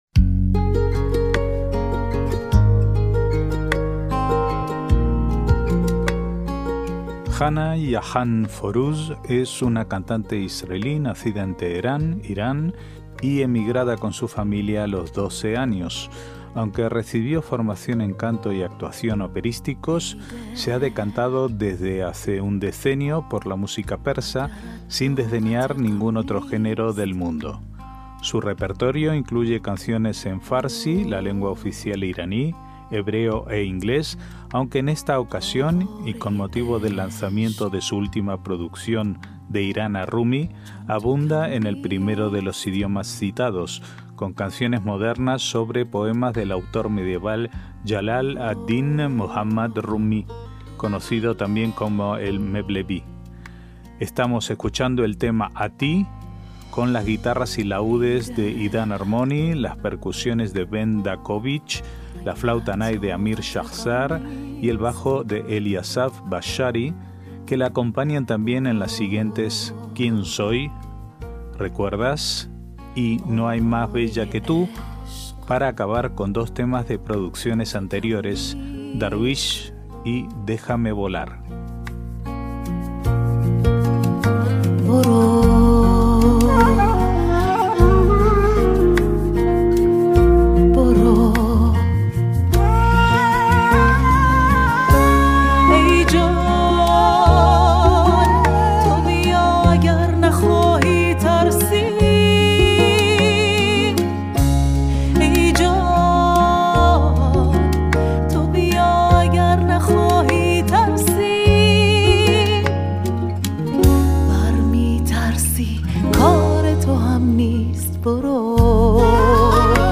MÚSICA ISRAELÍ
guitarras y laúdes
percusiones
flauta nay